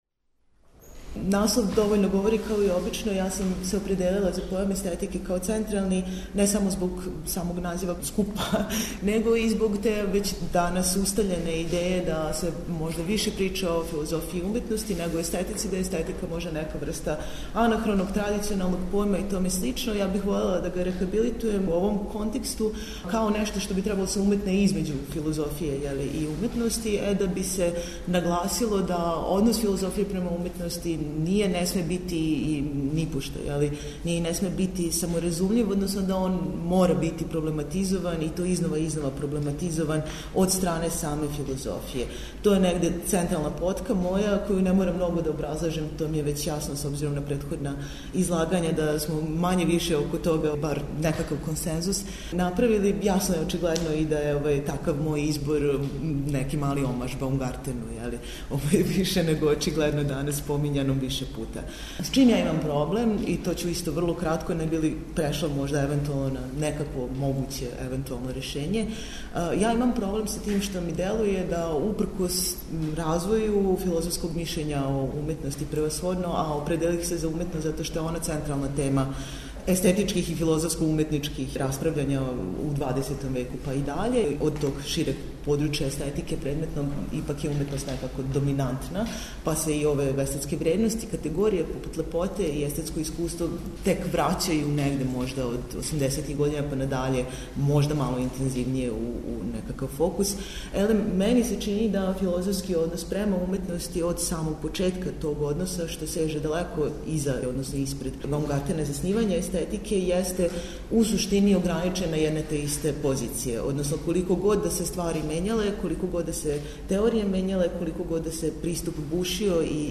Снимак је забележен 17. октобра прошле године у Новом Саду.